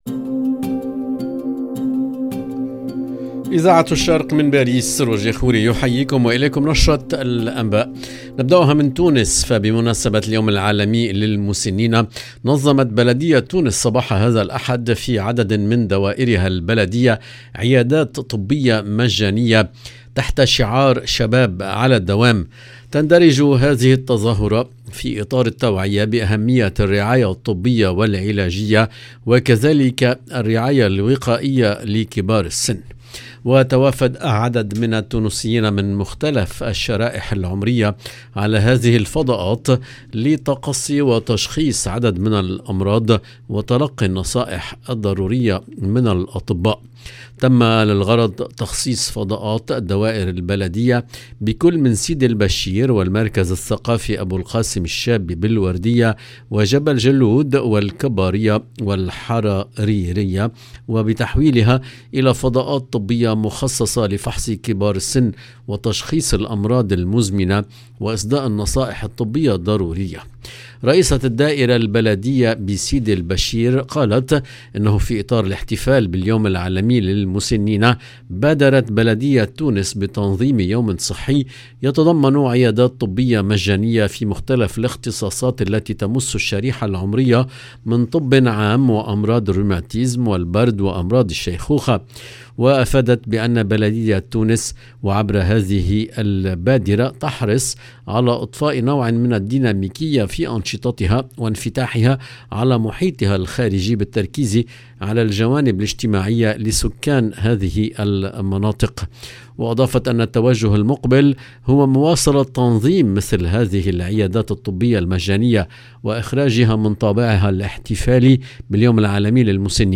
LE JOURNAL DU SOIR EN LANGUE ARABE DU 2/10/2022